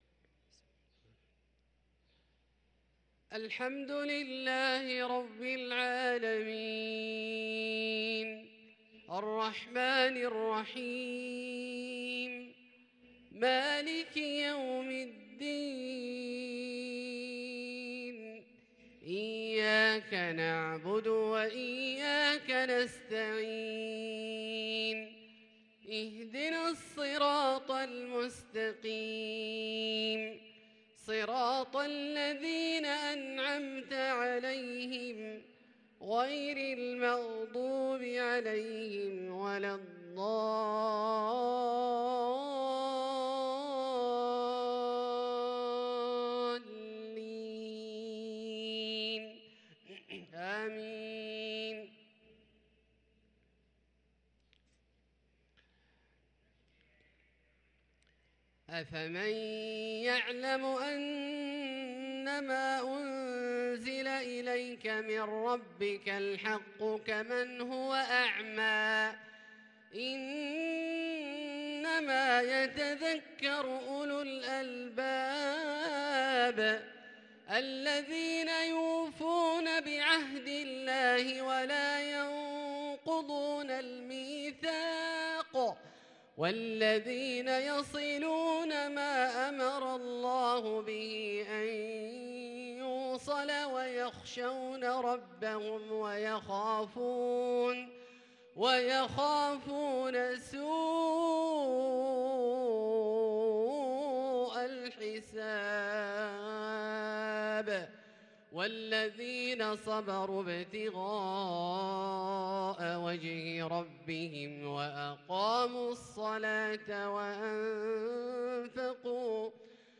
صلاة العشاء للقارئ عبدالله الجهني 13 جمادي الآخر 1444 هـ